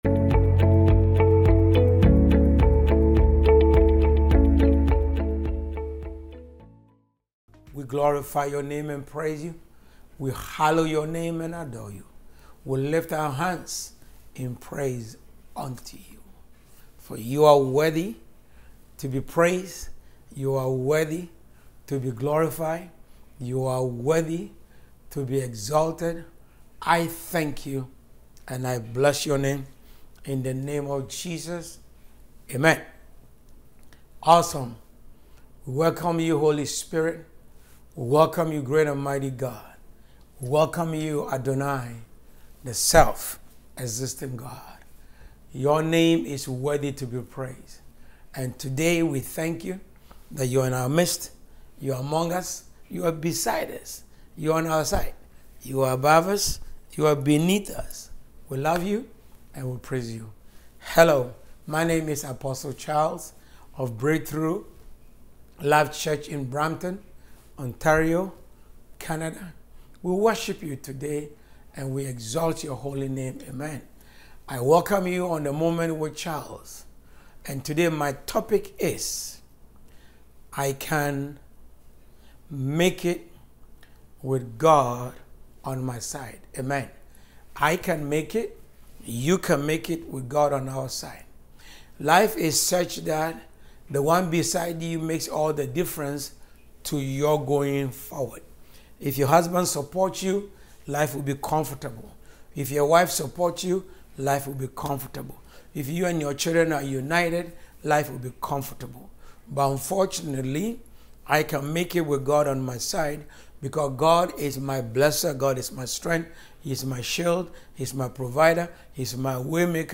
The main message is about having faith that one can succeed and overcome challenges with God’s support, emphasizing the idea that “I can make it with God on my side.” The speaker offers prayers, encouragement, and blessings to the listeners.